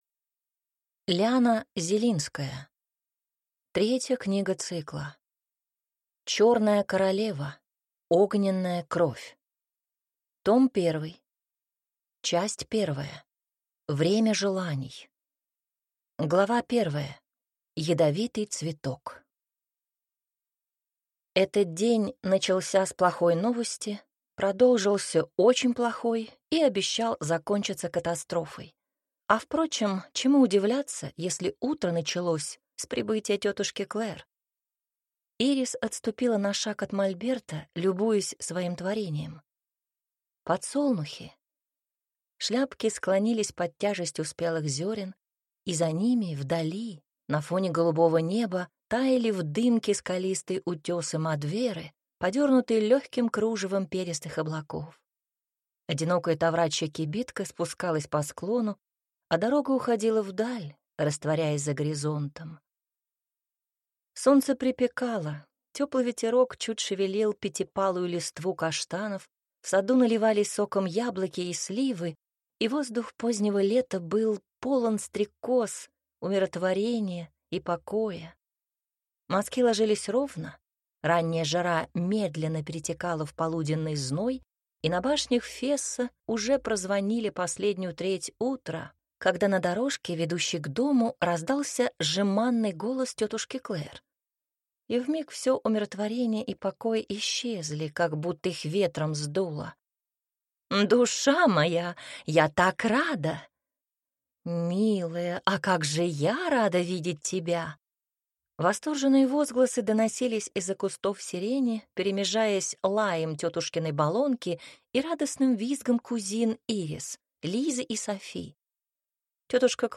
Аудиокнига Огненная кровь. Том 1 | Библиотека аудиокниг